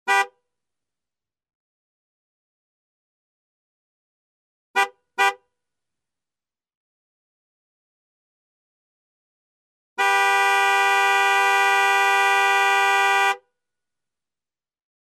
Звуки нивы
На этой странице собраны характерные звуки автомобиля Нива: рев двигателя, сигнал, скрип дверей и другие.